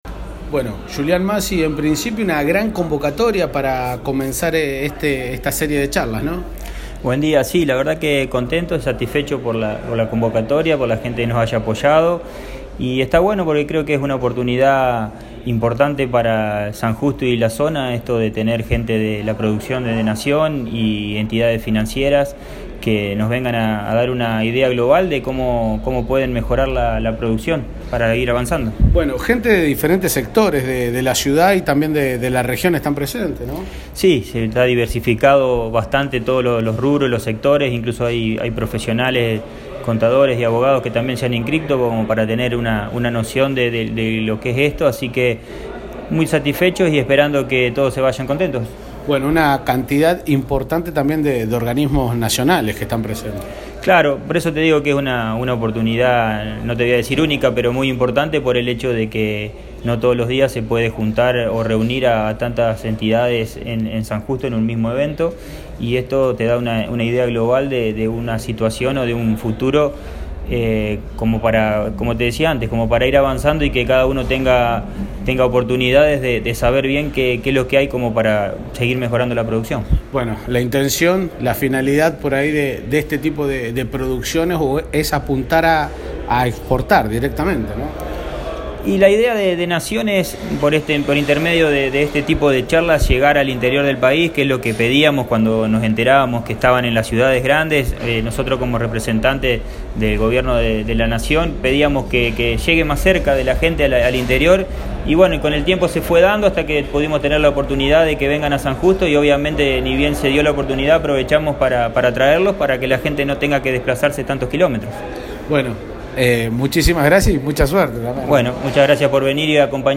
¿Necesitas herramientas para impulsar tus proyectos? es el nombre de la jornada que se realiza en el Club de Emprendedores de San Justo.
Escuchá a Julian Massi, Concejal de la ciudad de San Justo.
Julian-Massi-disertación.mp3